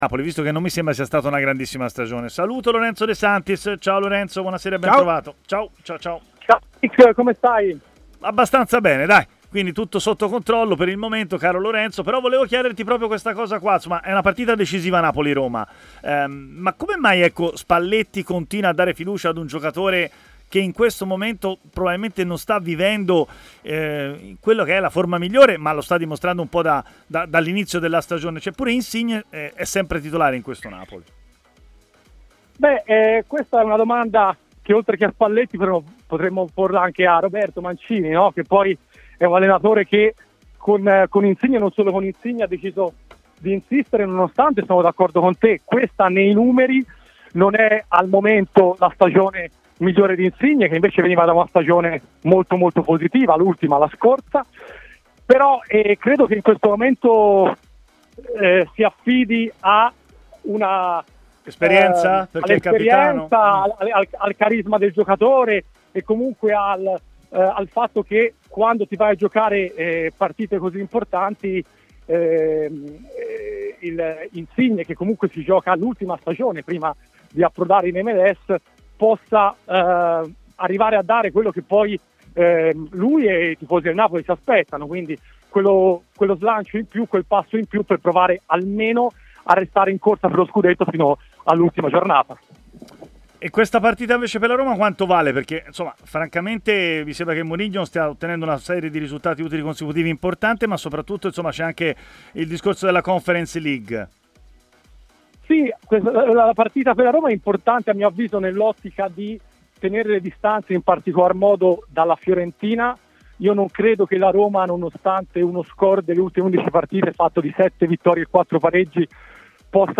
Intervenuto a TMW Radio